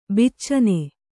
♪ biccane